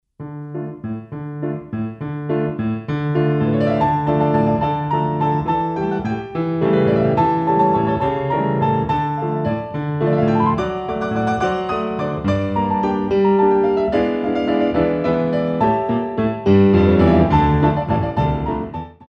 Latine